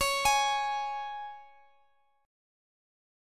Db5 Chord
Listen to Db5 strummed